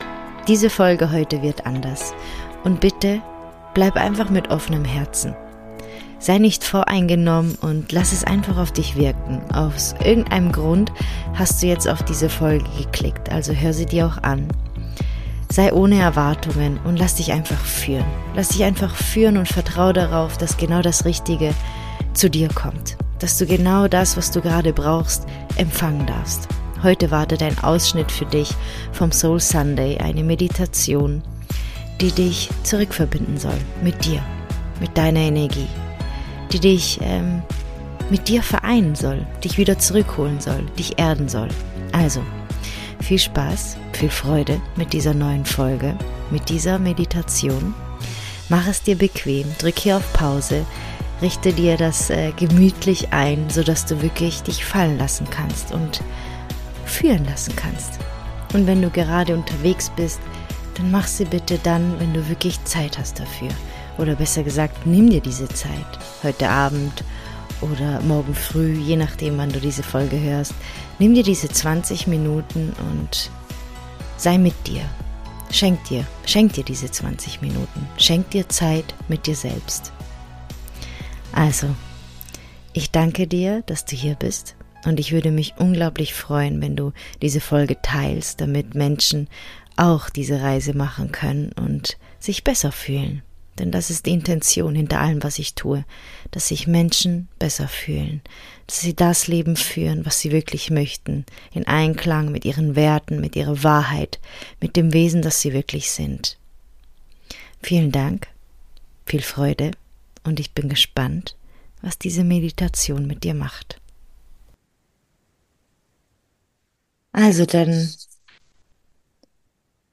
#91 Rückverbindung mit deiner Essenz - Eine geführte Meditation ~ IMPULS Podcast